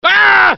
Download Aargh Half Life sound effect for free.